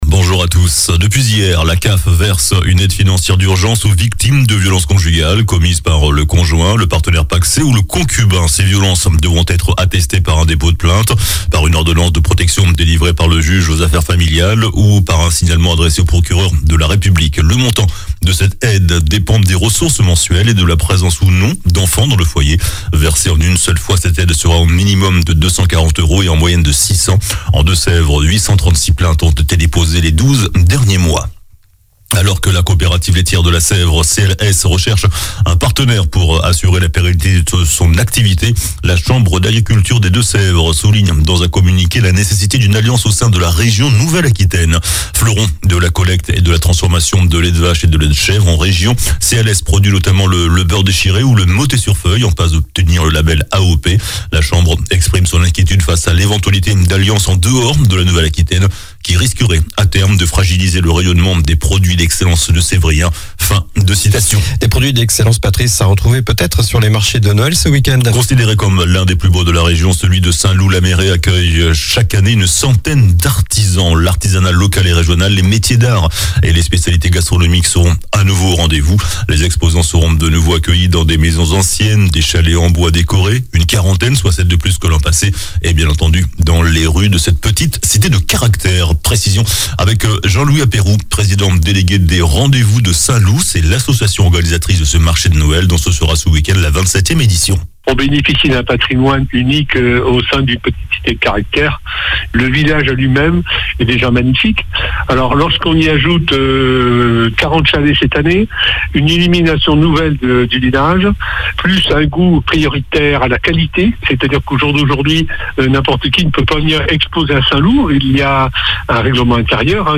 JOURNAL DU SAMEDI 02 DECEMBRE